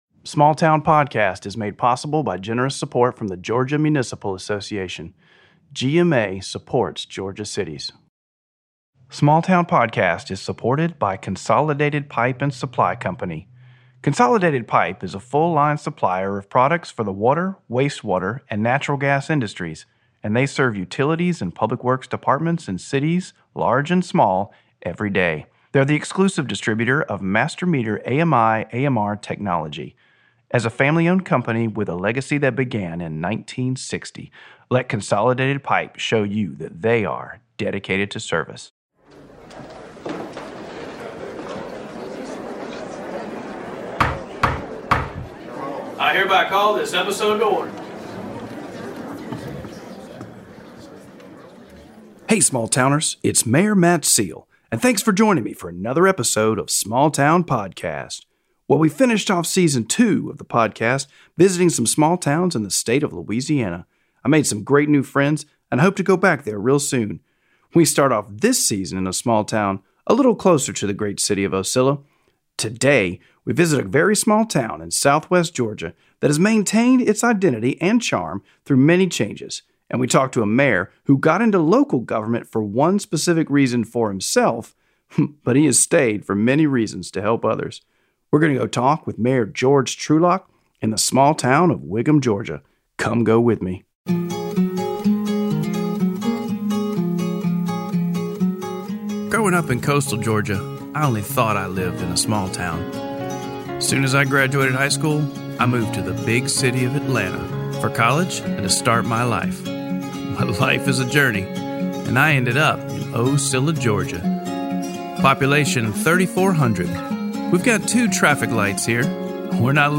Facebook Twitter Headliner Embed Embed Code See more options Ocilla Mayor and Small Town Podcast Host Matt Seale travels to Whigham, GA to talk with Mayor George Trulock. The 2 discuss how Mayor Trulock got involved in local government for one specific reason and why he continued to serve so many years. They also discuss how changes in the school affect community identity and what Whigham has done to maintain theirs in the face of changes.